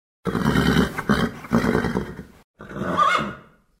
Звуки ржания лошади